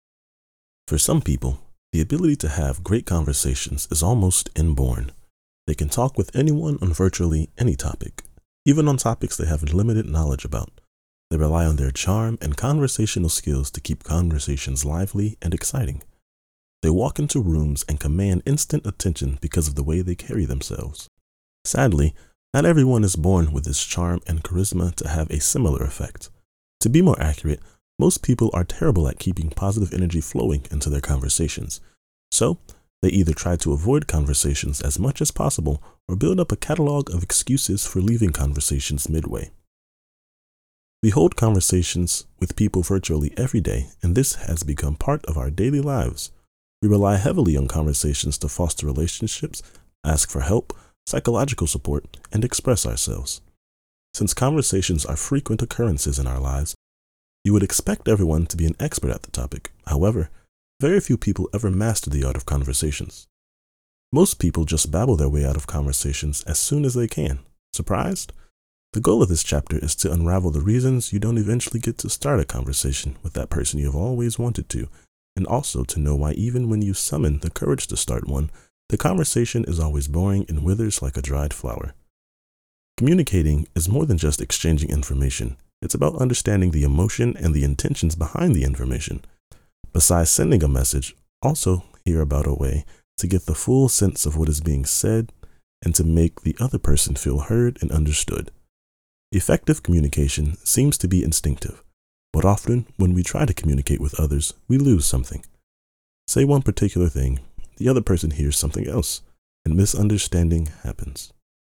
Communication Training
US Southern, US General
Young Adult